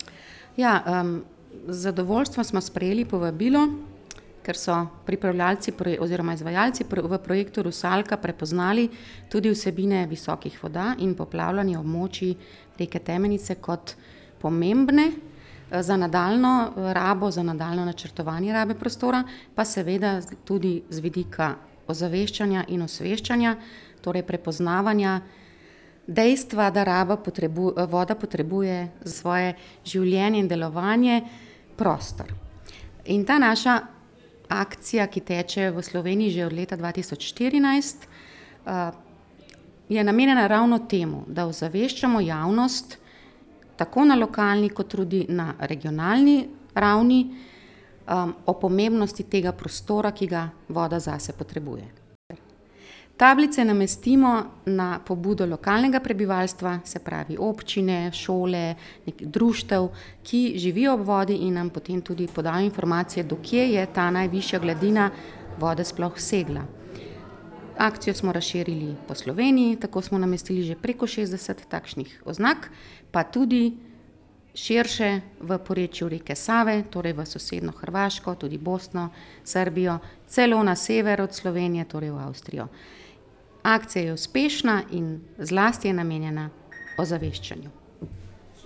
Zbrane je nagovorila županja Občine Trebnje Mateja Povhe in med drugim poudarila: